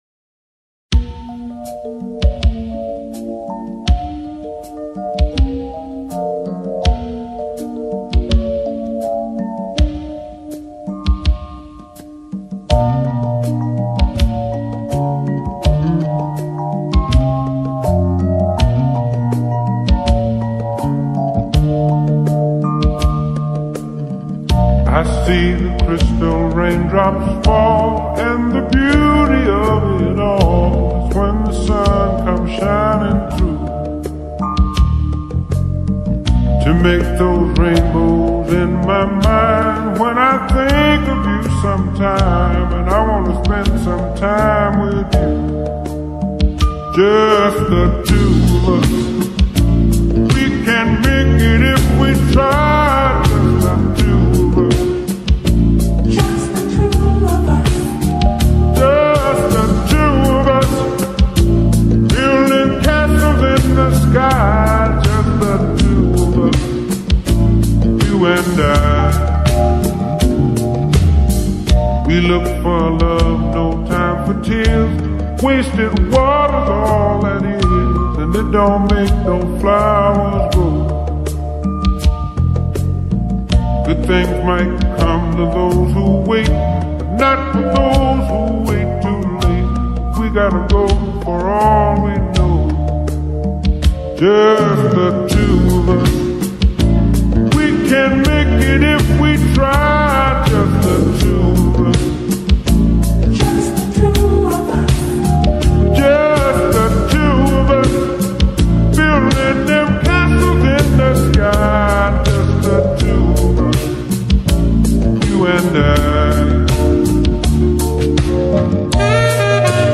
با ریتمی کند شده
عاشقانه